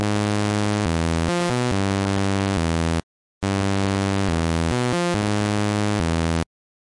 Tag: 140 2step 320 的dubstep 未来的车库 旋律